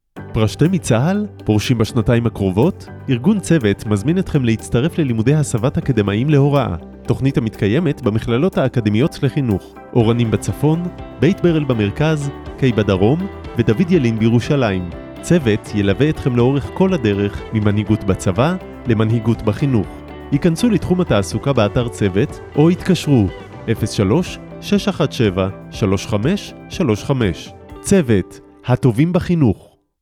יחידת התעסוקה של "צוות" העלתה לאמצעי התקשורת תשדיר פרסומת על תוכניות ההסבה להוראה, המתקיימות בארבע מכללות ברחבי הארץ.